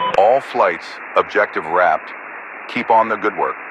Radio-commandObjectiveComplete5.ogg